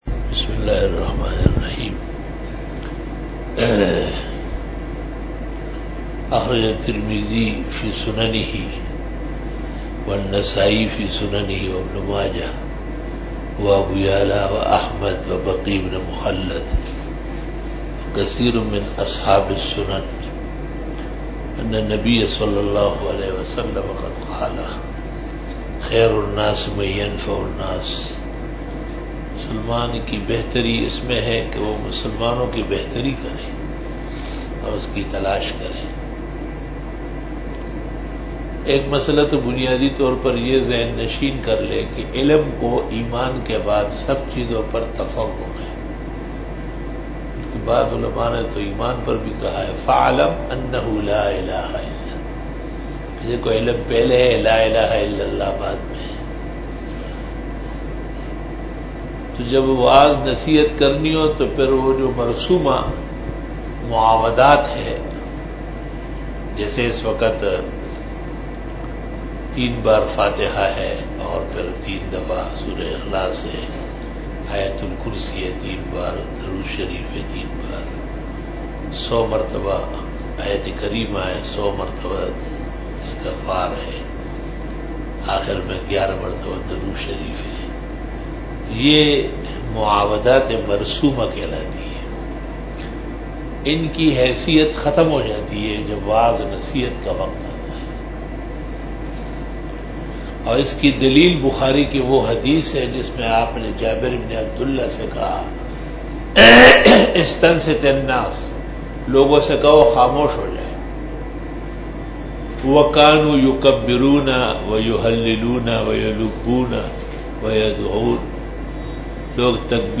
بیان بعد نماز فجر بروز ہفتہ 29 جمادی الاول 1441ھ/ 25 جنوری 2020ء"